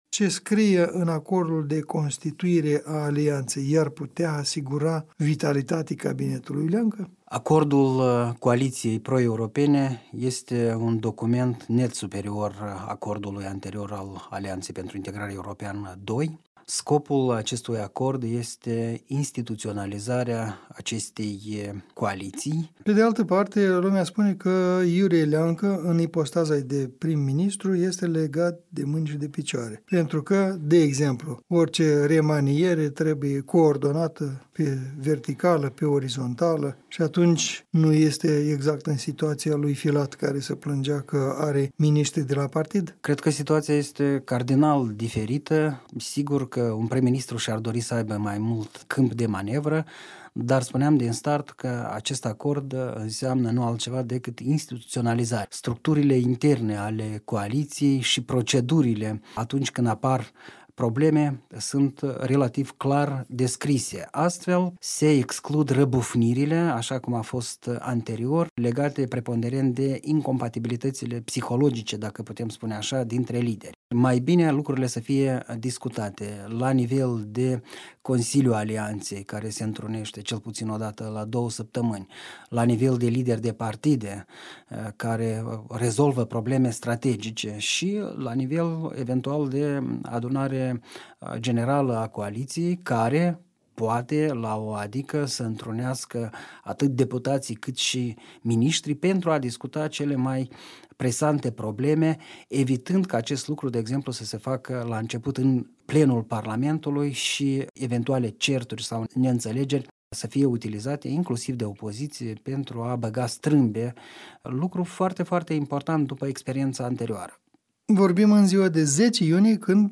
în dialog